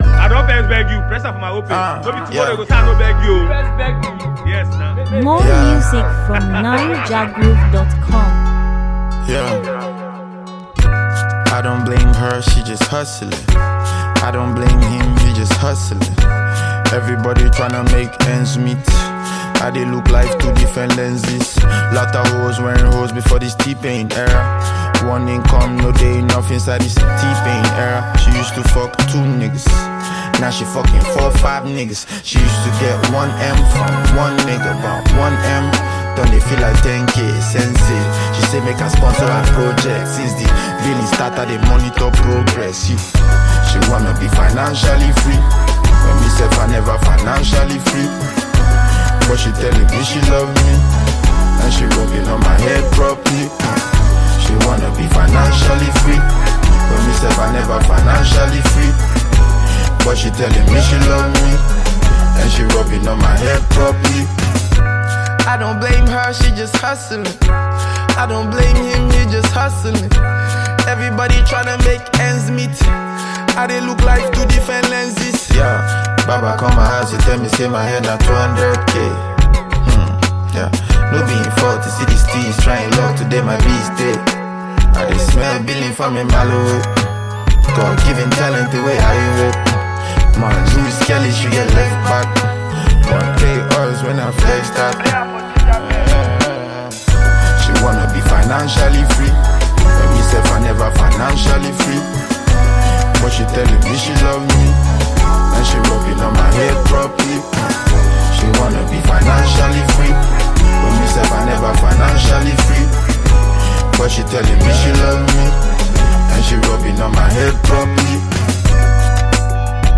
Nigerian singer and rapper